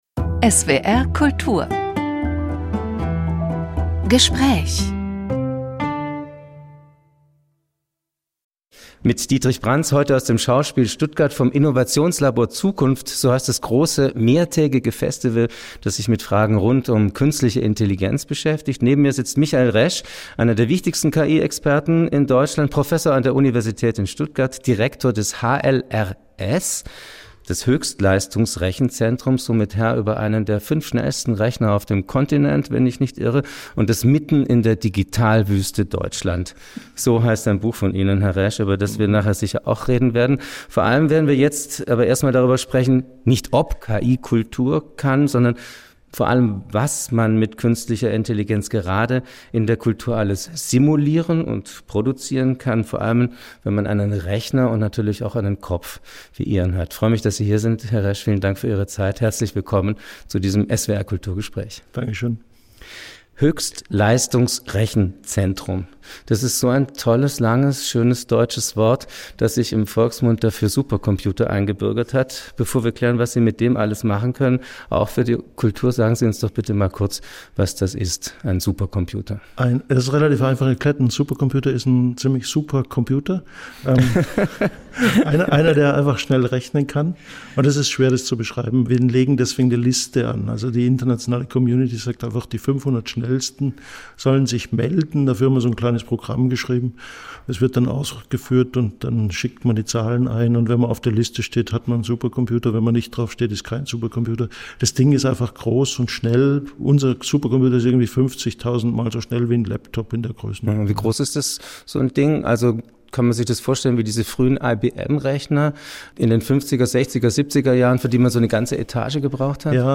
(Öffentliche Veranstaltung vom 29. Mai 2025 im Schauspiel Stuttgart)